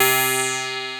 DOBLEAD C4-R.wav